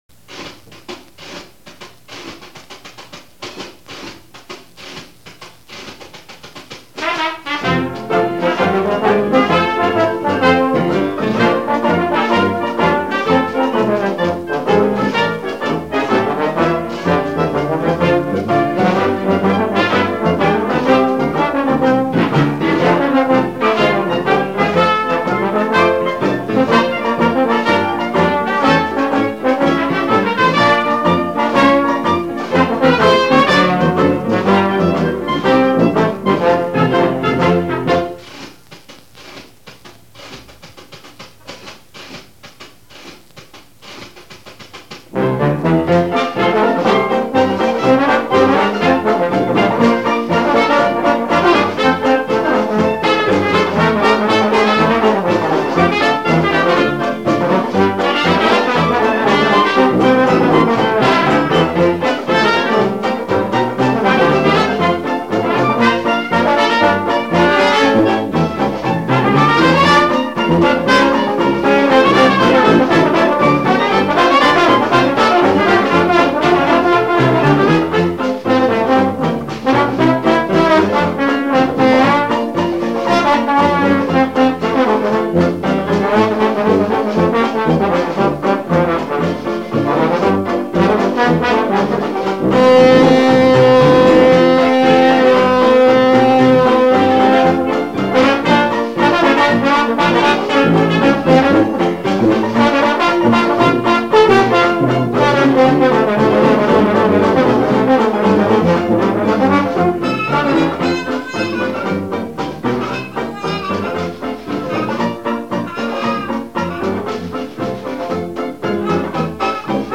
You can hear two outfits that I played with in Wilmington, Delaware.
march .
trombone